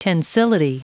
Prononciation du mot tensility en anglais (fichier audio)
Prononciation du mot : tensility